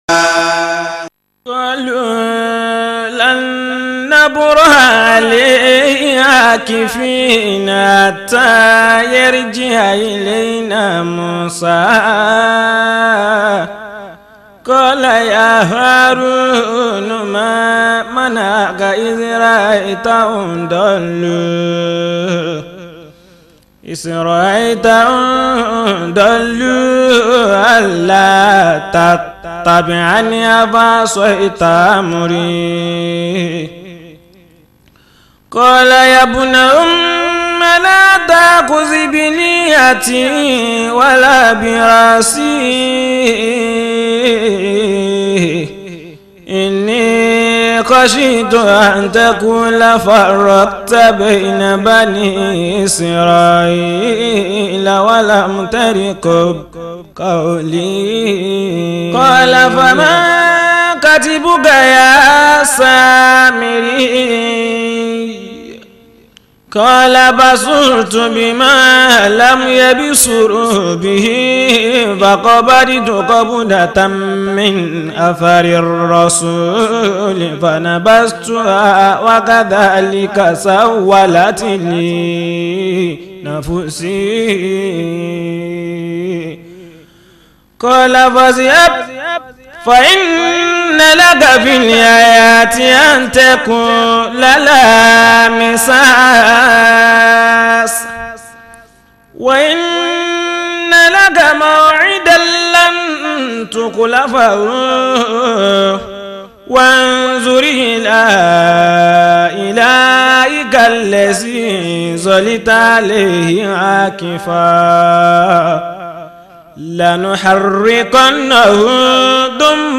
Yoruba recitation